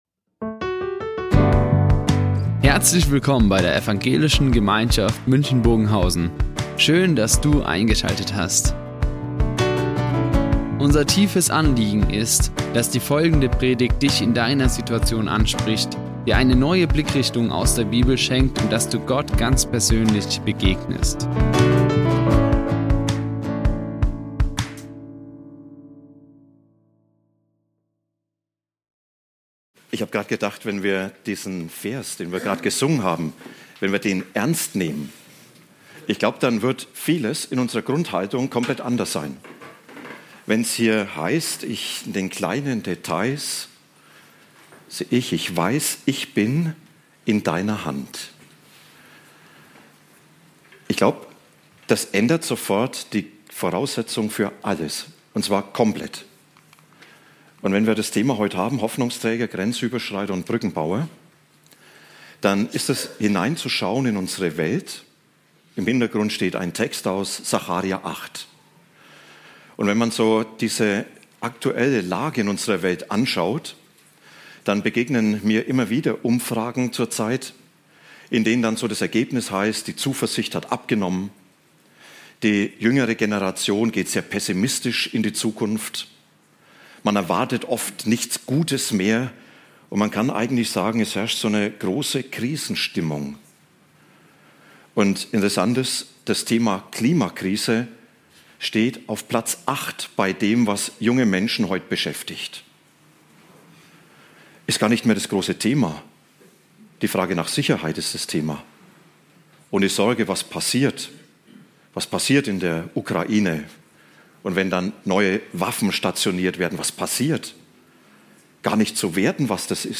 Brückenbauer" Die Aufzeichnung erfolgte im Rahmen eines Livestreams.